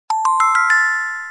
Sons système / System sounds